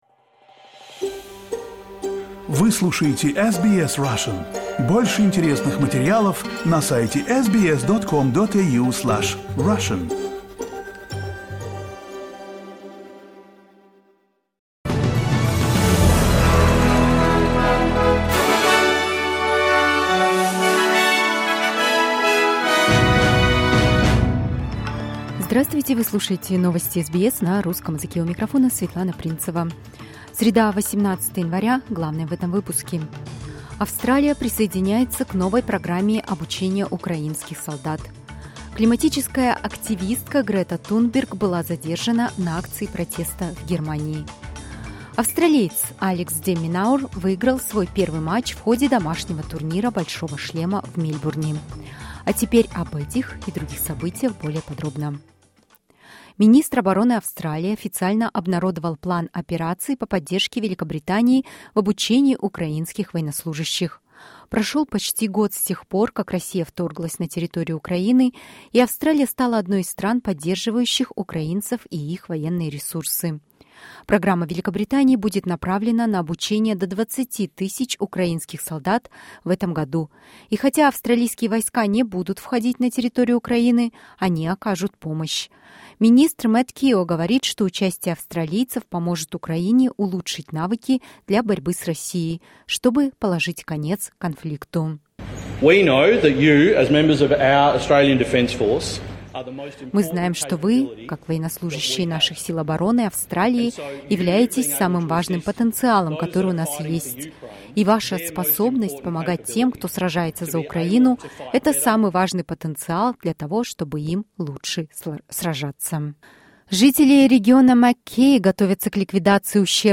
SBS news in Russian — 18.01.2023